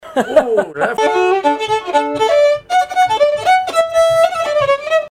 Pièces instrumentales à plusieurs violons
Pièce musicale inédite